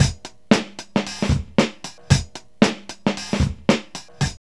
KEEN FUNK114.wav